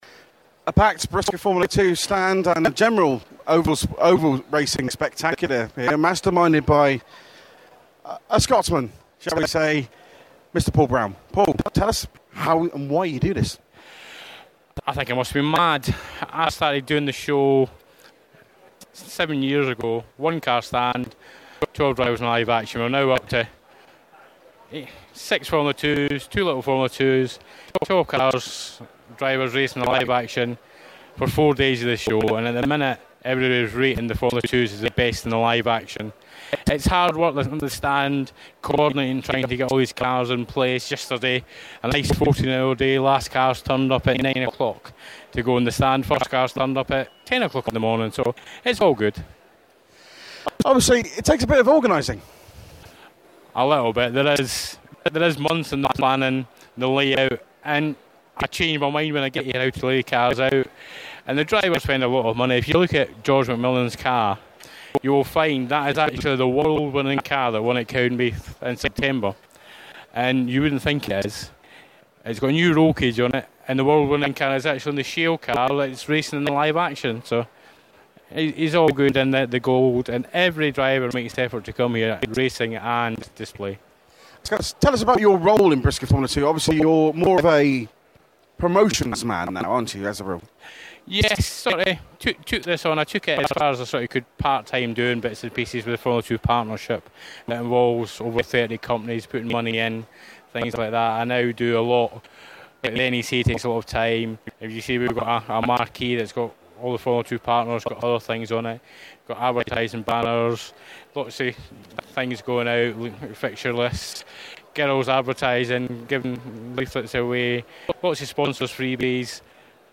Autosport Show- Interview